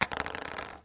乒乓球 " 乒乓球 1
描述：14号塑料乒乓球从6英寸下降。到一张木桌上。记录到逻辑 16/441
Tag: 离奇 PON克 节奏 声音 紧凑